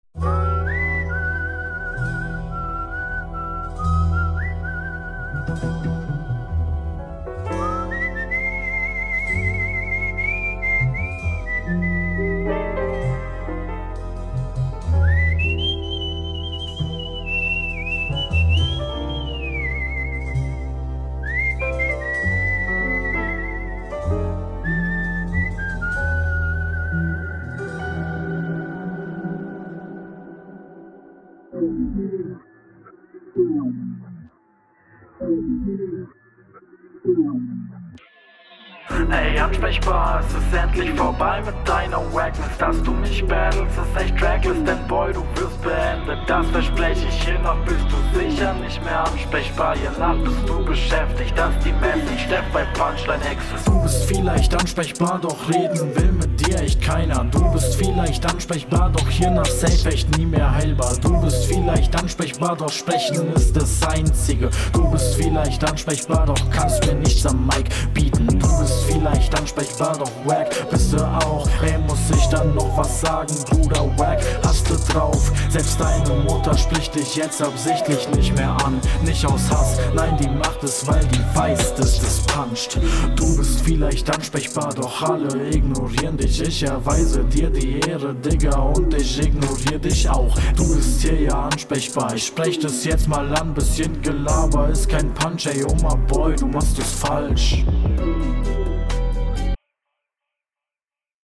Wieder eine sehr langes Intro.
:D Egal zur Bewertung: Flow ist gut.